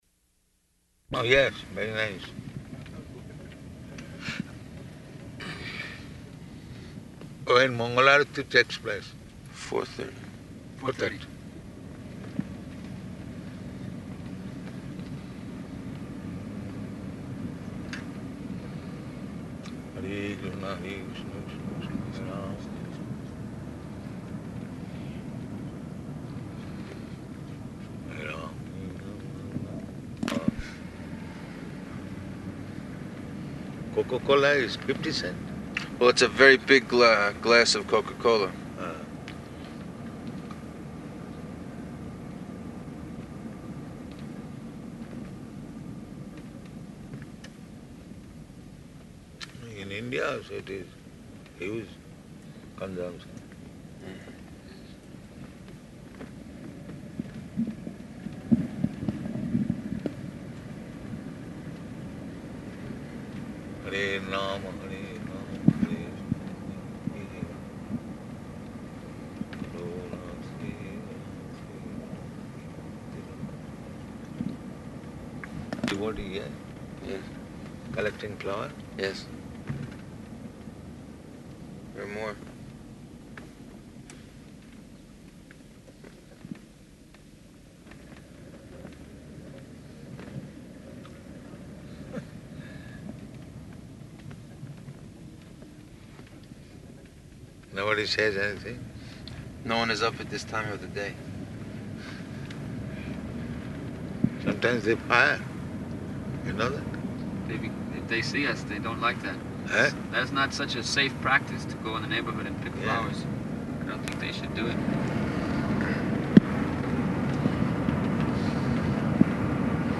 Morning Walk --:-- --:-- Type: Walk Dated: June 29th 1975 Location: Denver Audio file: 750629MW.DEN.mp3 [in car] Prabhupāda: ...yes, very nice.